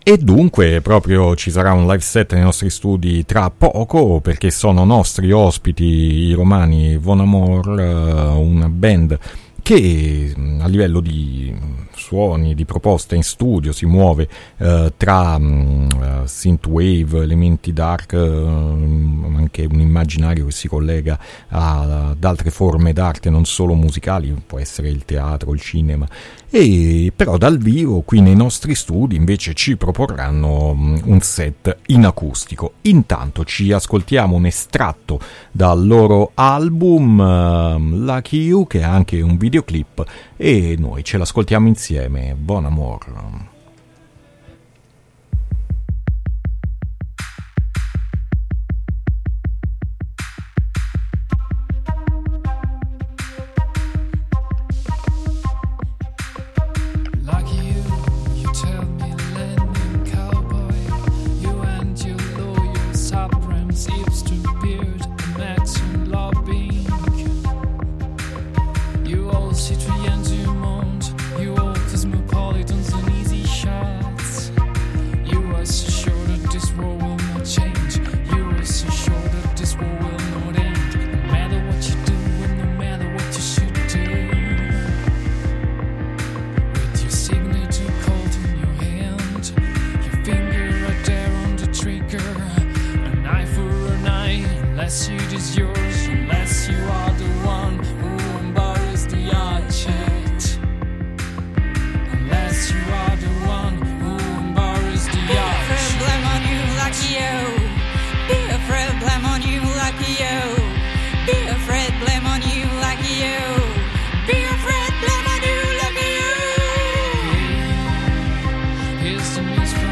efficace sound synth wave dark
suonando anche alcuni brani in acustico